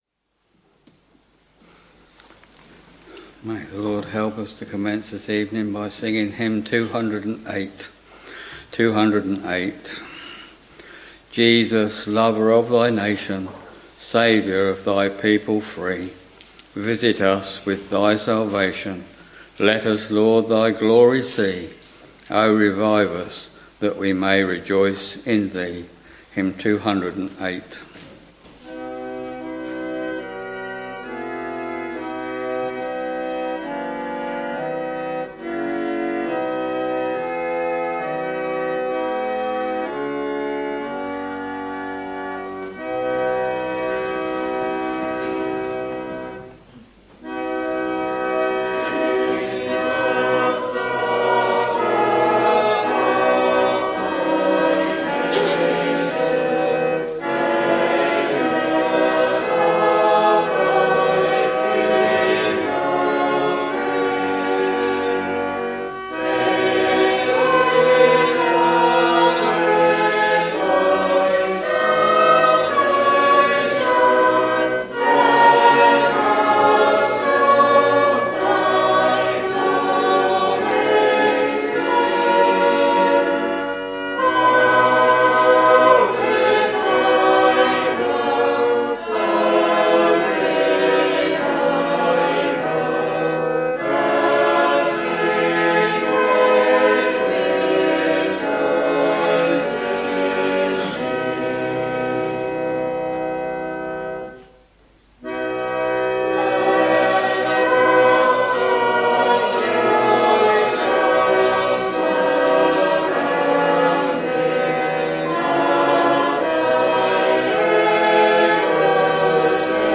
Sunday, 10th November 2024 — Evening Service Preacher